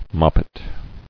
[mop·pet]